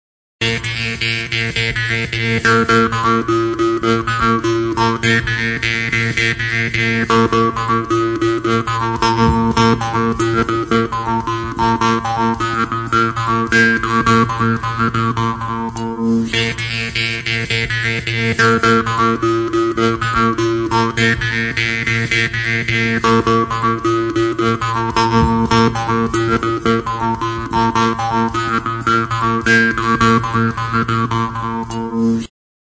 Music instrumentsBIRIMBAO; TROMPA
Idiophones -> Plucked / flexible -> Without sound board
BIRIMBAO; TROMPA
Altzairuzko tronpa arrunta da.